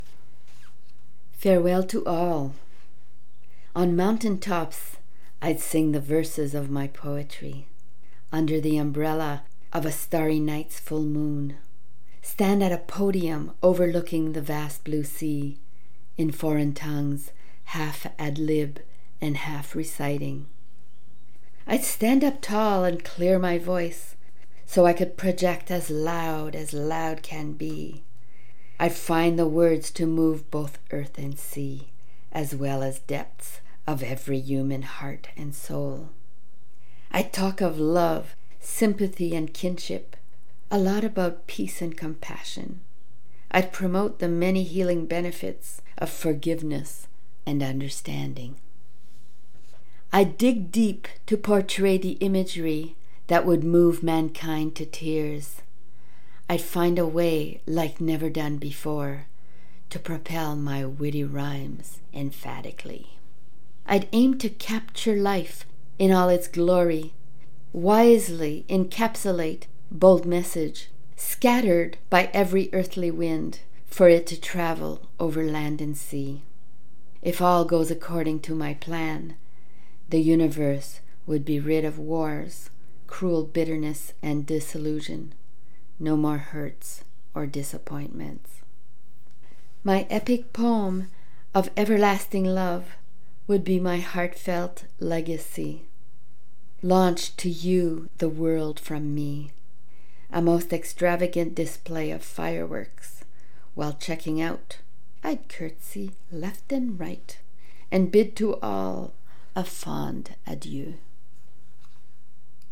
Read on air by invitation  ~  April 14, 2021  'WORDS & MUSIC'